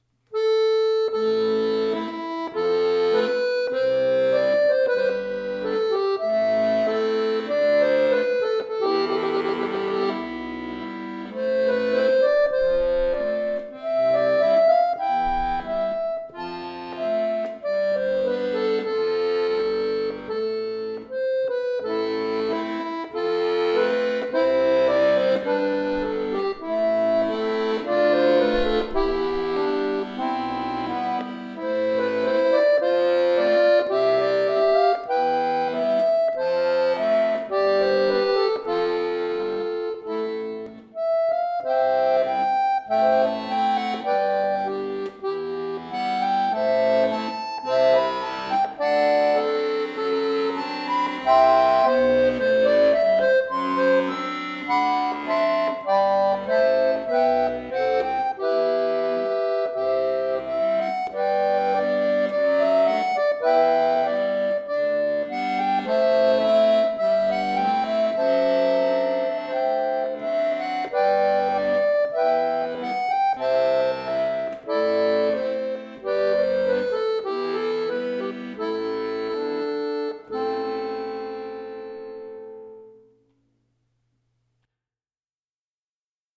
Folk
Irish